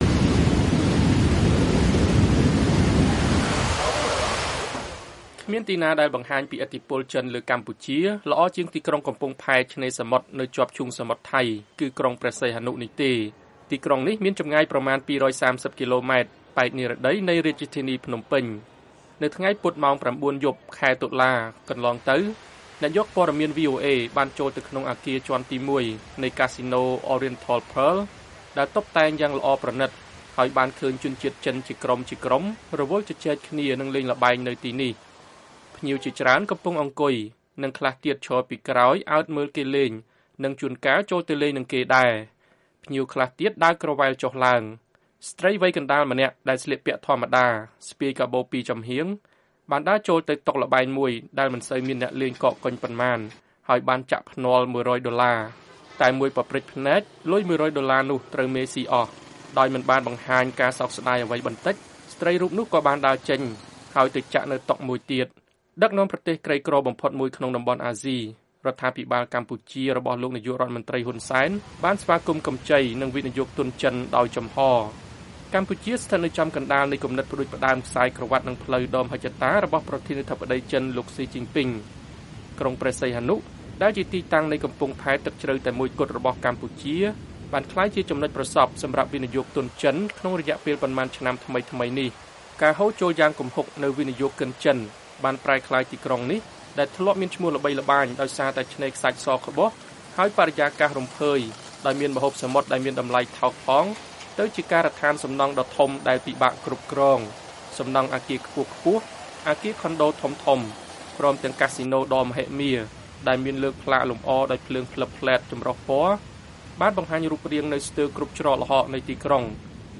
សេចក្តីរាយការណ៍ជាសំឡេង៖ លុយនិងពលរដ្ឋចិន កំពុងផ្លាស់ប្តូរក្រុងកំពង់ផែរបស់កម្ពុជា